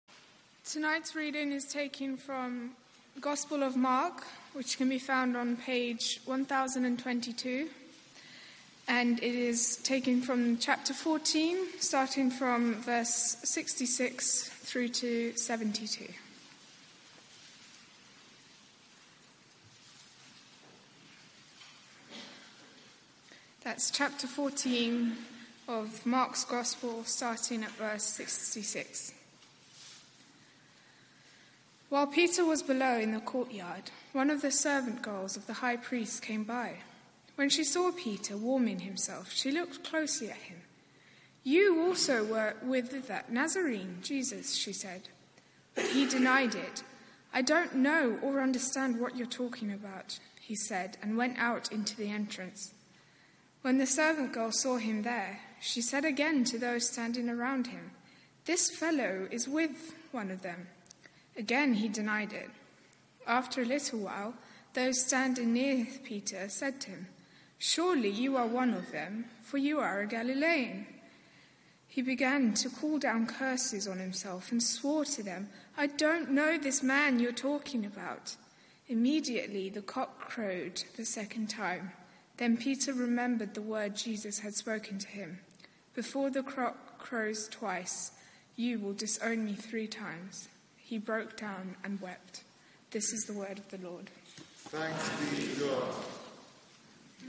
Media for 6:30pm Service on Sun 04th Mar 2018 18:30
ReadingPlay